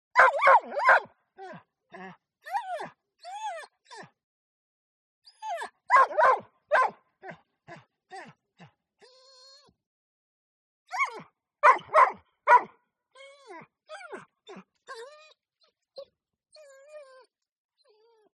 جلوه های صوتی
دانلود صدای سگ غمگین و ناراحت از ساعد نیوز با لینک مستقیم و کیفیت بالا